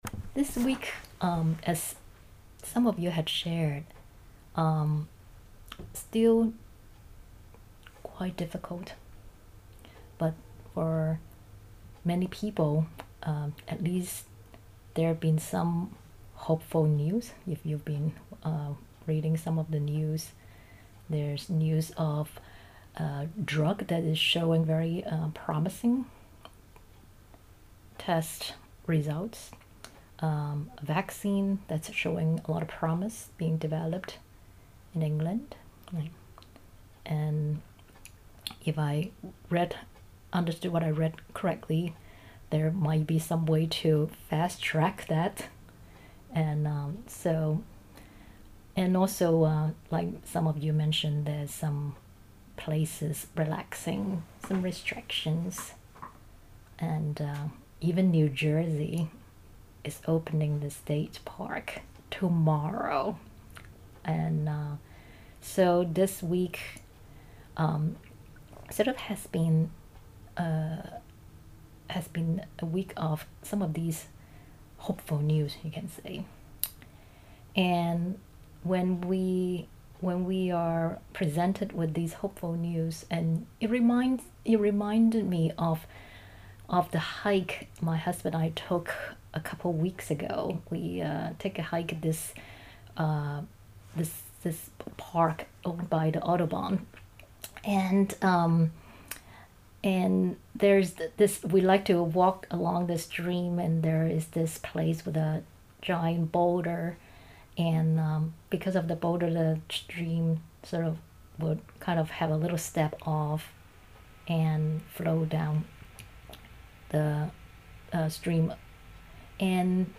This talk was given in the weekly online Dharma practice gathering held on May 1, 2020.